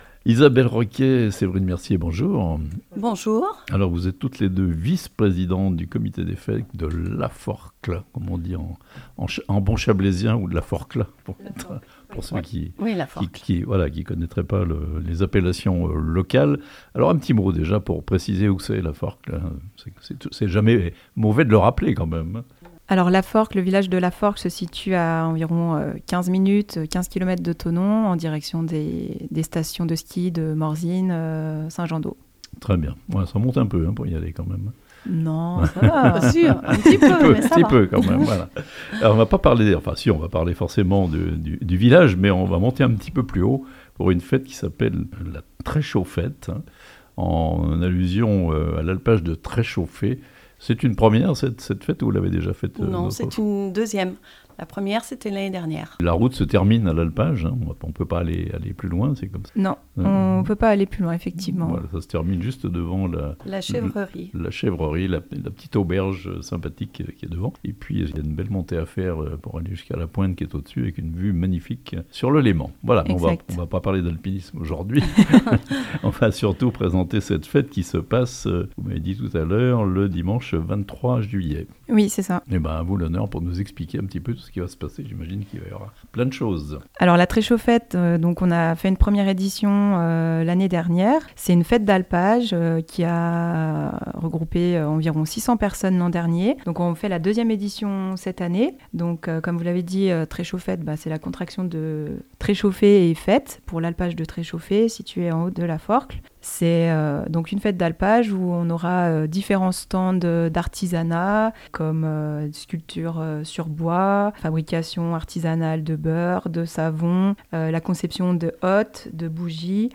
L'Alpage de Tréchauffé (La Forclaz) en fête le dimanche 23 juillet (interviews)